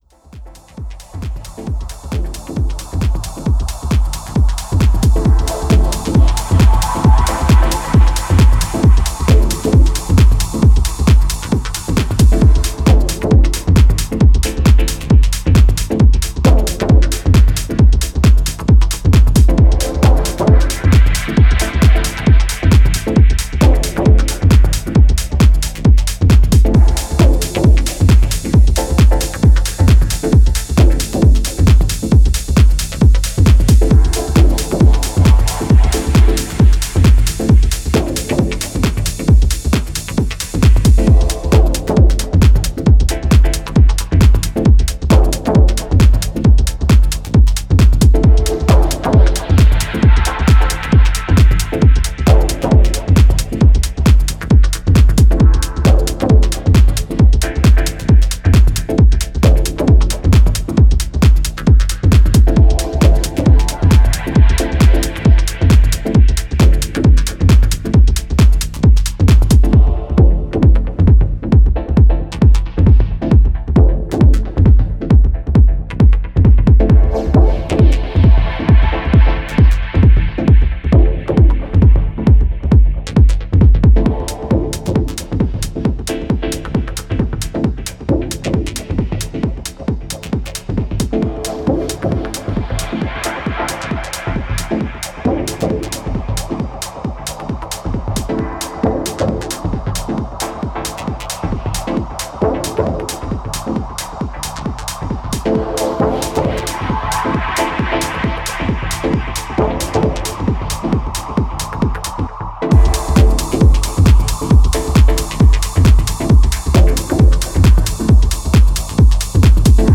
this EP ventures into lighter, deeper territories
dreamy pads and pulsating chords
Electro Techno